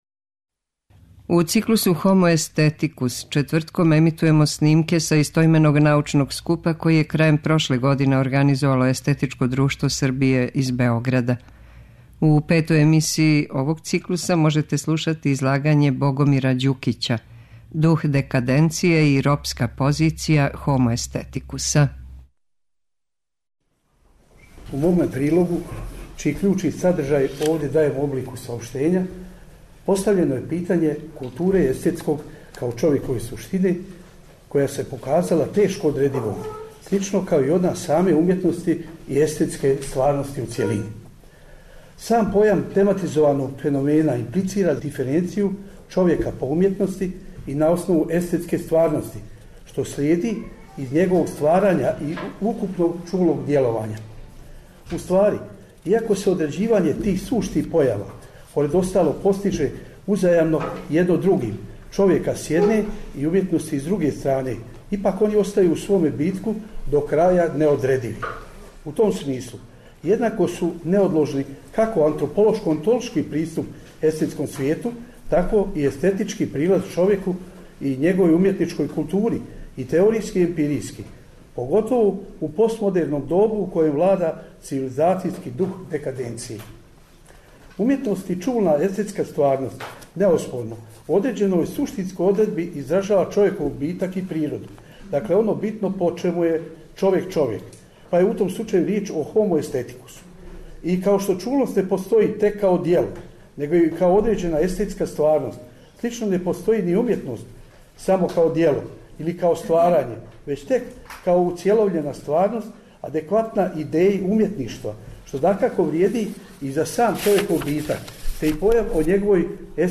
У циклусу HOMO AESTHETICUS четвртком ћемо емитовати снимке са истоименог научног скупа који је, у организацији Естетичког друштва Србије, одржан 22. и 23. децембра у Заводу за проучавање културног развитка у Београду.
Научни скупoви